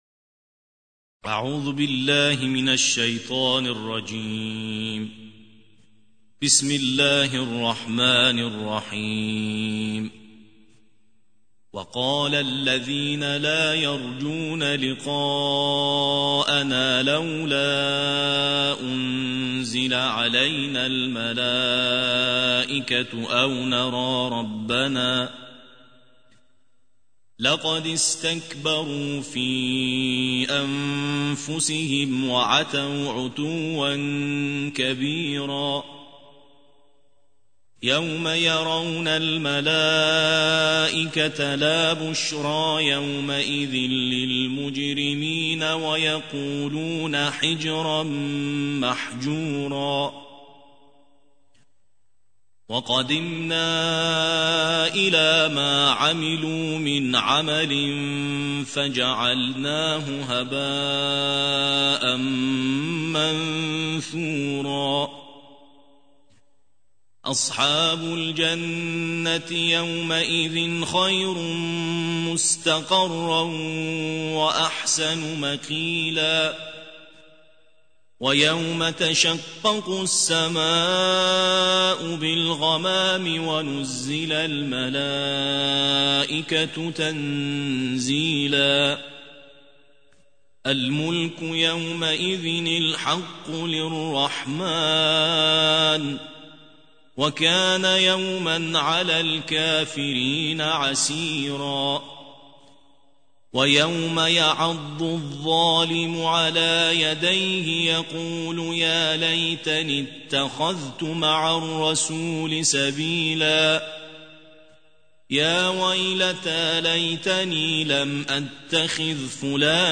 الجزء التاسع عشر / القارئ